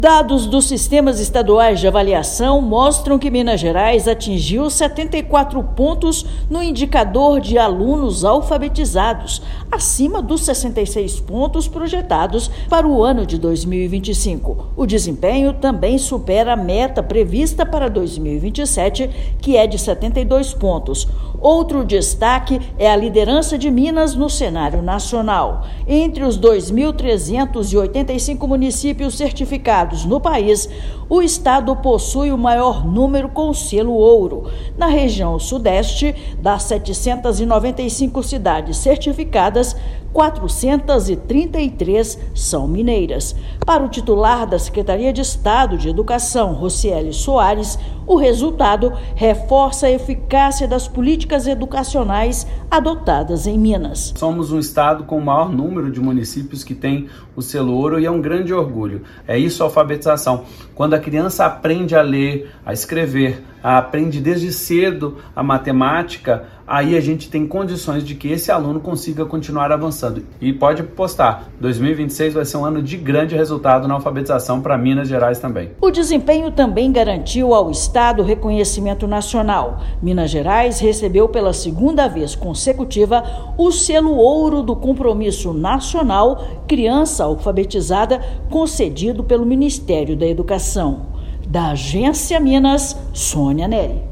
Com 74 pontos no indicador, estado supera metas e reúne 433 dos 2.385 selos ouro do Brasil, consolidando protagonismo nacional. Ouça matéria de rádio.